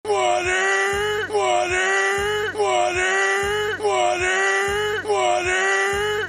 Spongebob Water Sound Button: Meme Soundboard Unblocked
Spongebob Water